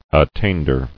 [at·tain·der]